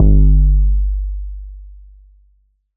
DDWV 808 3.wav